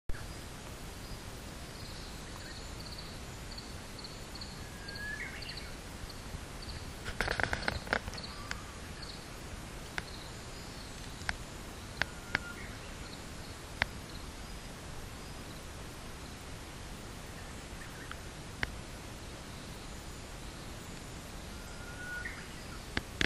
ウグイスが鳴き始めた
ケチョ、ケチョ、ケチョ。
ウグイスの鳴き声